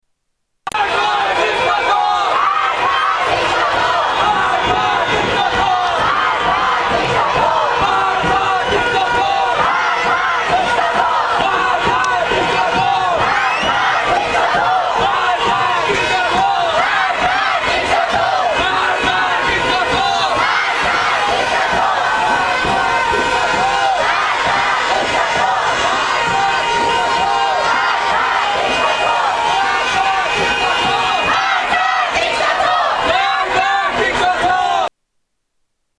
Tags: Historical Iran Election Protests Iran Presidential Election Mir-Hossein Mousavi